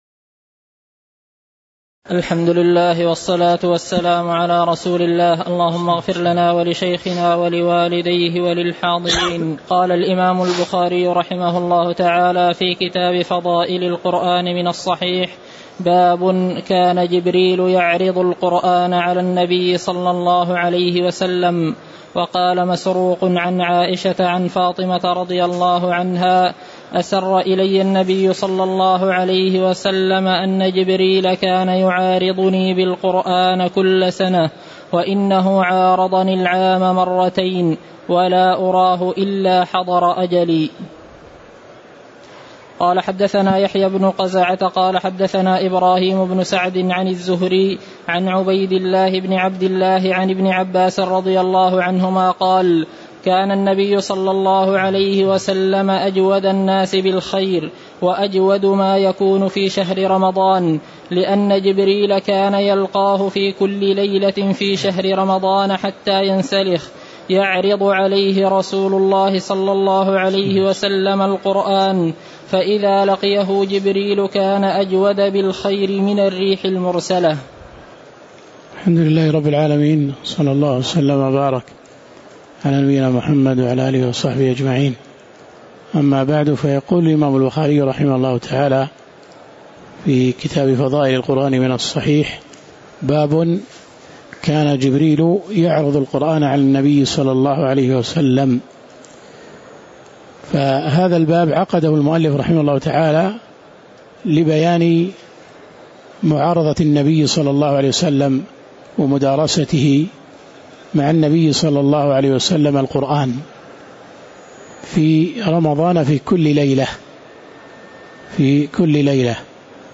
تاريخ النشر ١٥ رمضان ١٤٣٩ هـ المكان: المسجد النبوي الشيخ